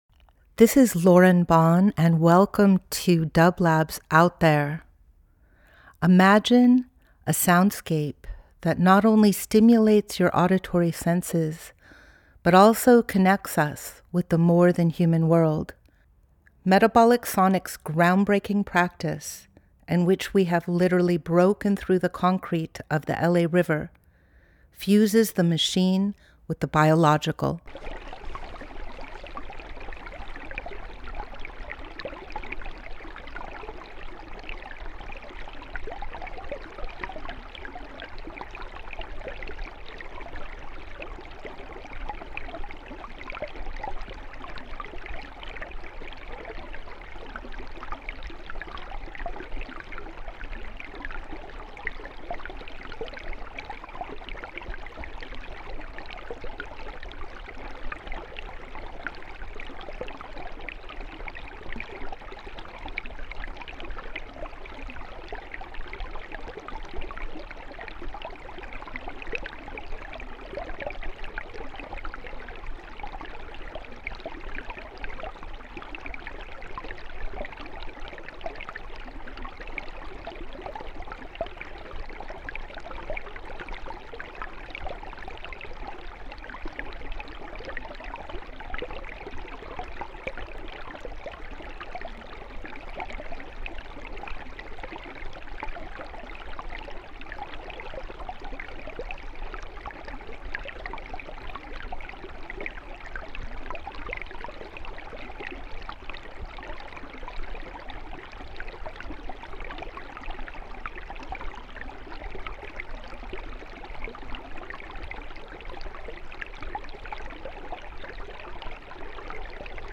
Out There ~ a Field Recording Program
Each week we present a long-form field recording that will transport you through the power of sound.
This field recording was made in the Owens River with an underwater microphone (hydrophone) below Aberdeen before it reaches Owens Lake. This is the fifth recording in a series of field recordings from various locations along the Los Angeles Aqueduct.